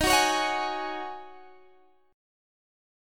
Listen to Ebm11 strummed